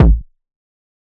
Kick (New Body).wav